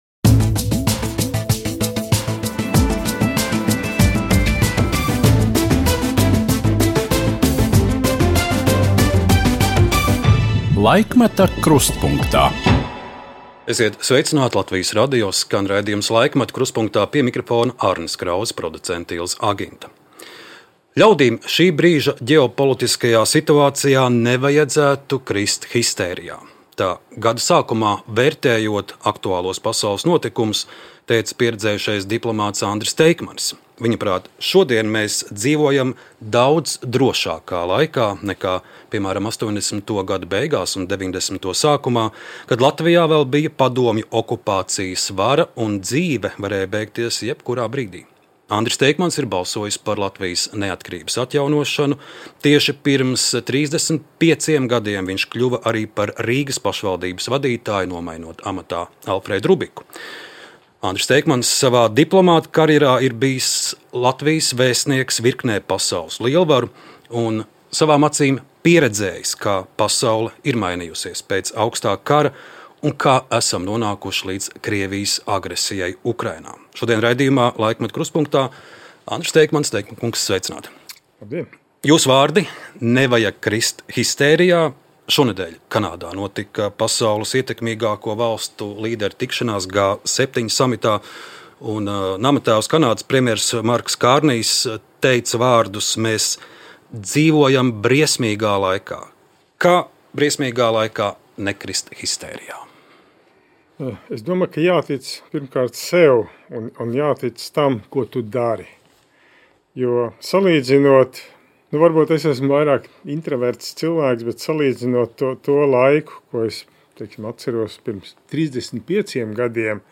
Saruna ar cilvēku, kuras dzimtai ir stipras saknes Latgalē.